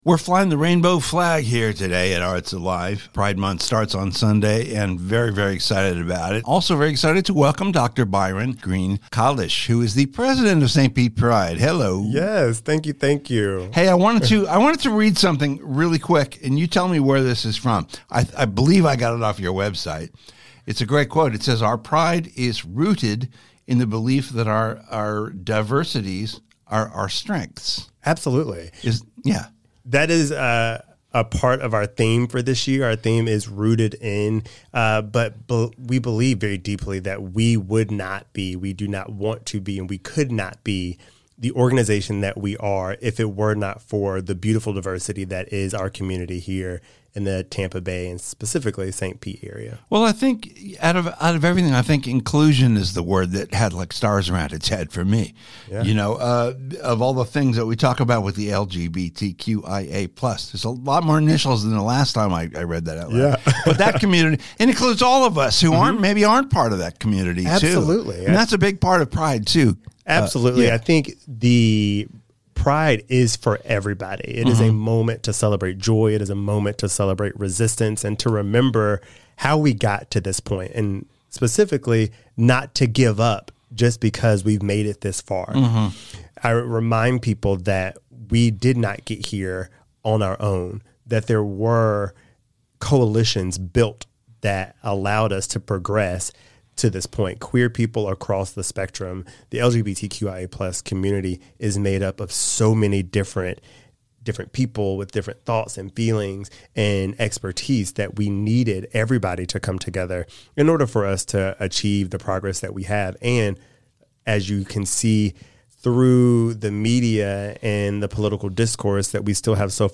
Radio St. Pete Podcast Archive / Arts Alive!